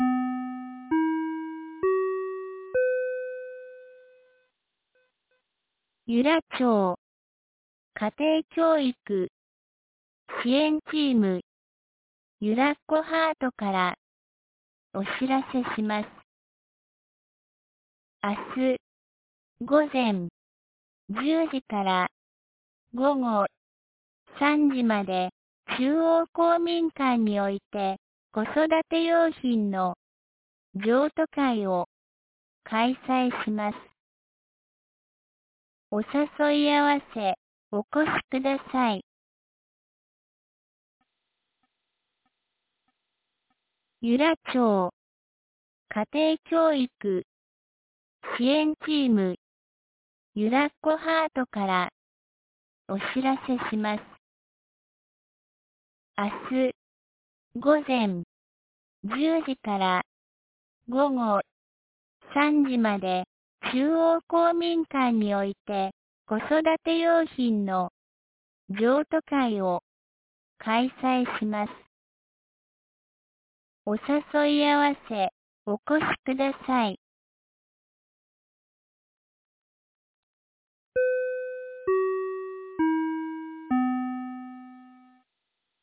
2025年09月19日 12時21分に、由良町から全地区へ放送がありました。